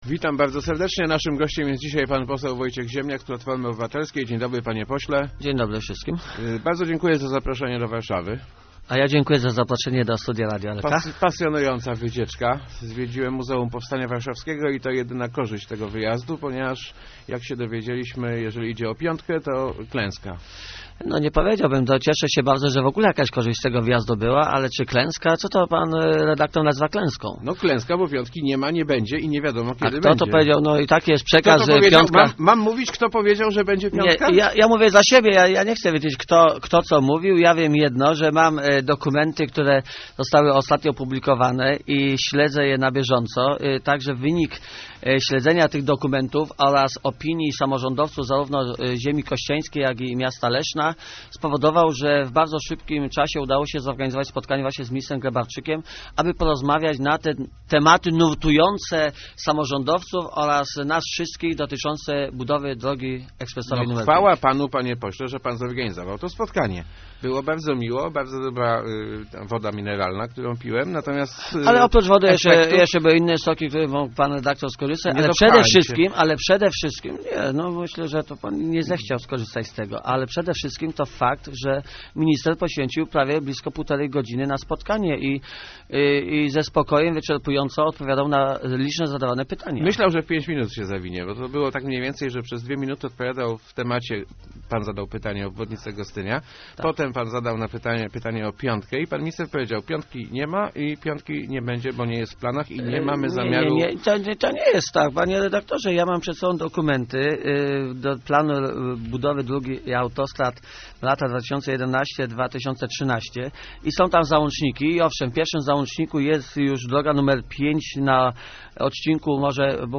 Nie bądźmy pesymistami - mówił w Rozmowach Elki poseł PO Wojciech Ziemniak. Jego zdaniem "Piątka" ma szanse na rozpoczęcie budowy przed 2013 rokiem.